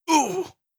14. Damage Grunt (Male).wav